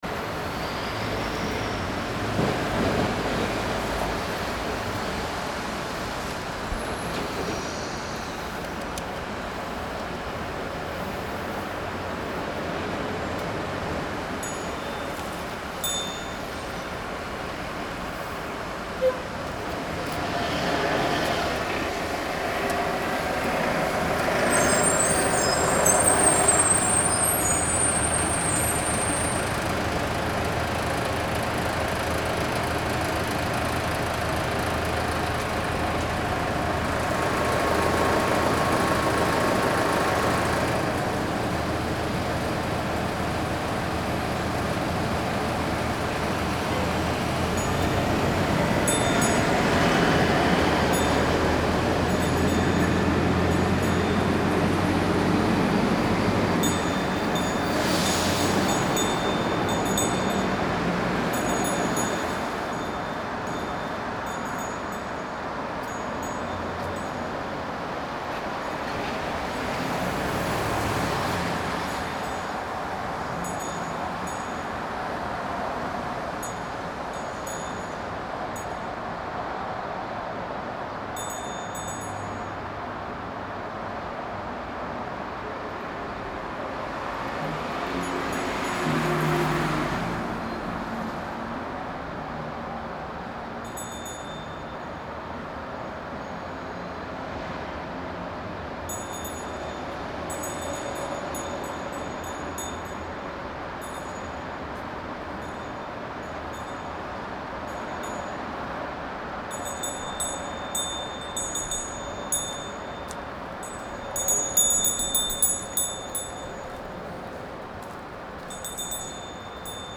The early morning soundscape is much denser here than in San Francisco. More machines, less birds. Yet it’s relatively still compared to the rest of the day in both cities.
This recording is from the front of the building on Broadway.